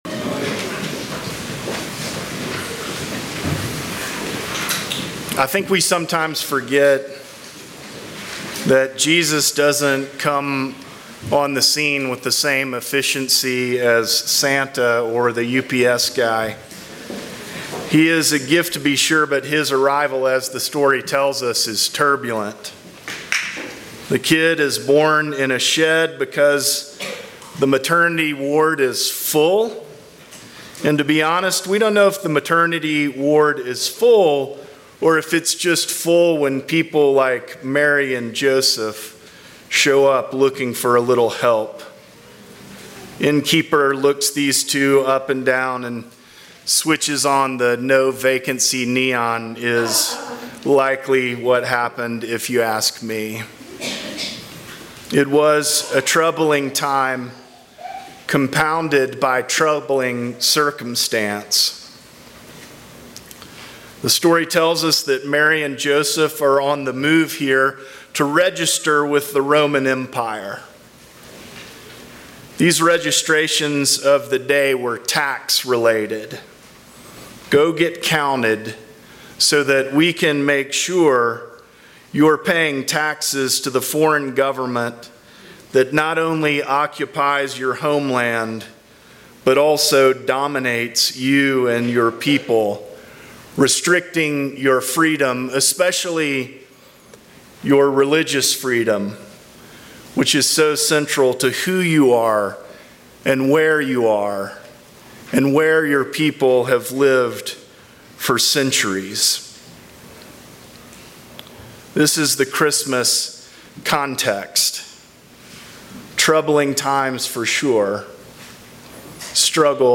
Sermons from St. John's Episcopal Church Merry Christmas!